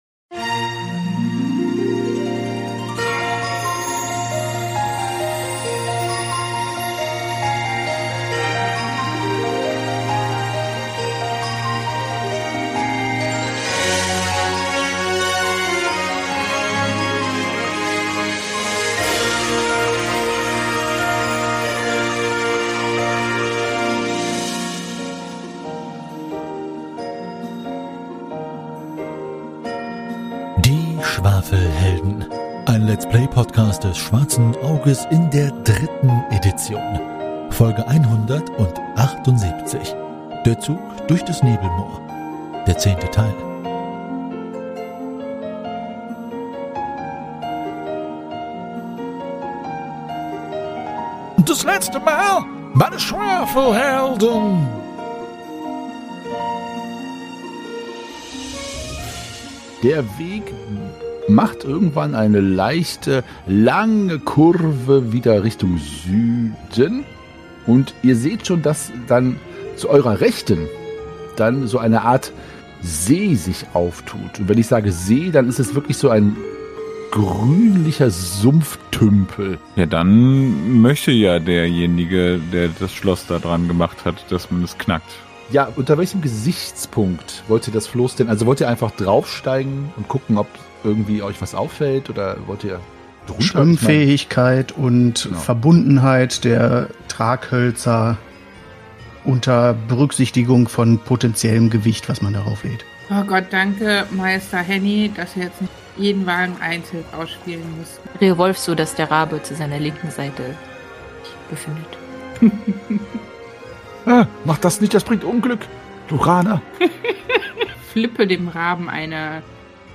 Ein Let’s Play-Podcast für Das Schwarze Auge, das deutsche Fantasy-Rollenspiel. Wir spielen DSA in der 3. Edition und haben uns vorgenommen, alle Abenteuer in Reihenfolge ihrer Veröffentlichung zu spielen.